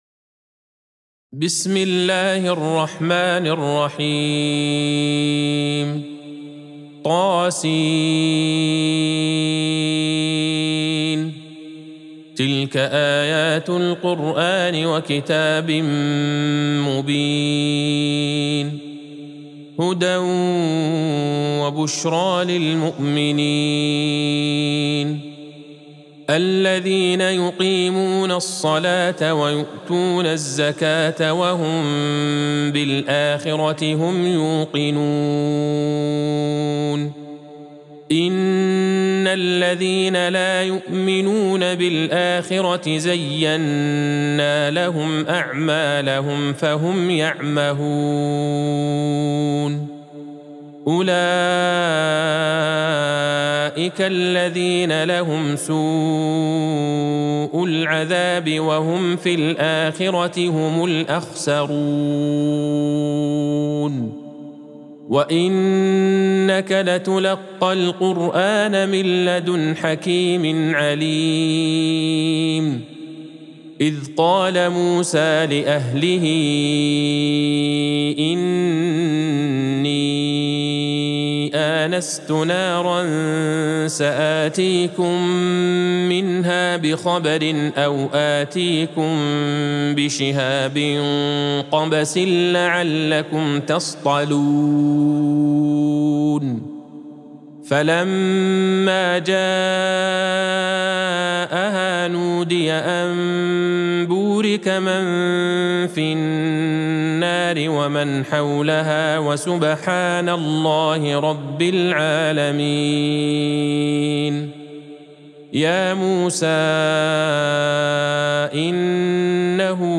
سورة النمل Surat An-Naml | مصحف المقارئ القرآنية > الختمة المرتلة